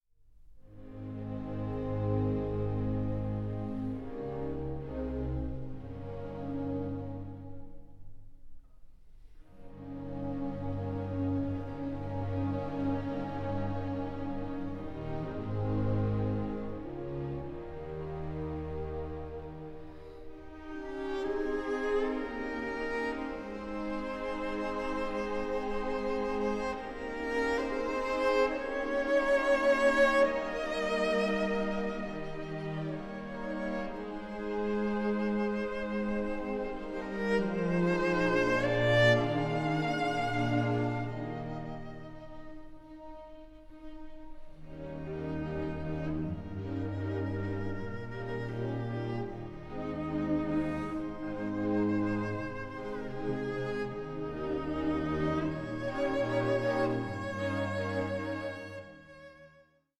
(48/24, 88/24, 96/24) Stereo  26,95 Select